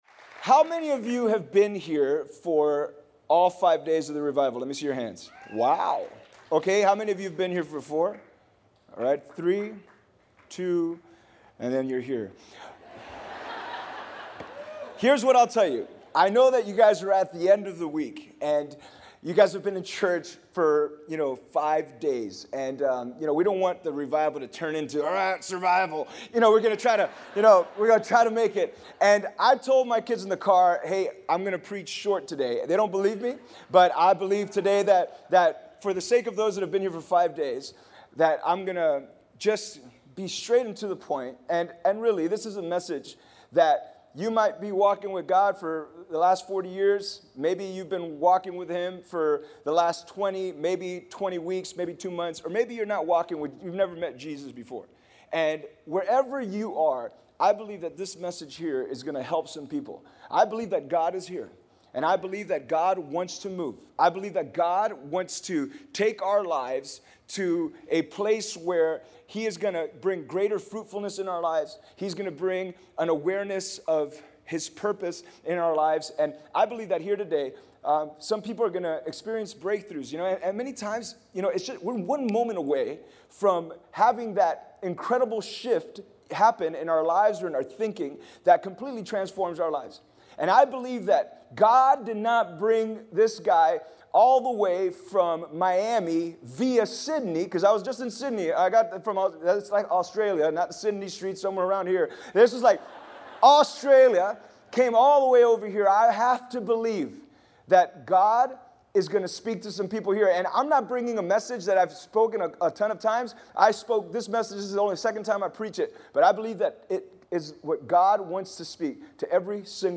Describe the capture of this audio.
5 Nights Of Revival (2014)